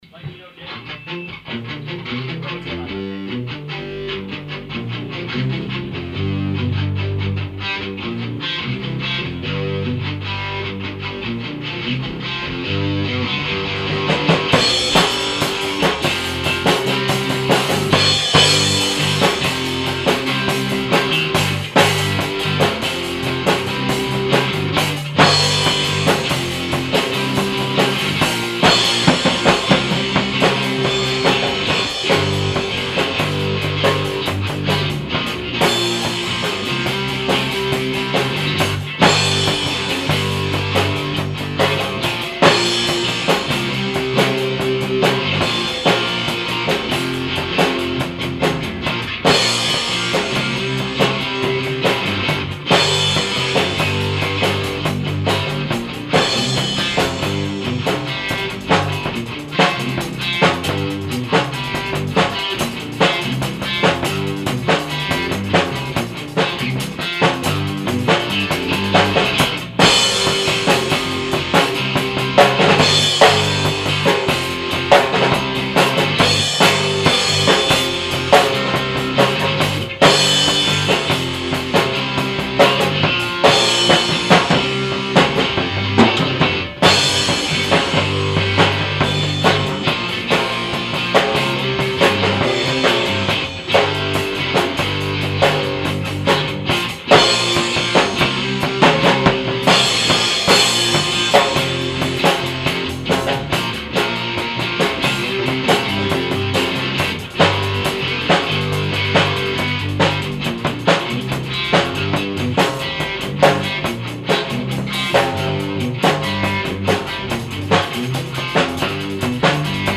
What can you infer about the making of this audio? During my senior year I got a band together and we tried to do the battle of the bands. Don't expect much- the sound quality isn't that great, and we hadn't been playing for very long when I recorded them.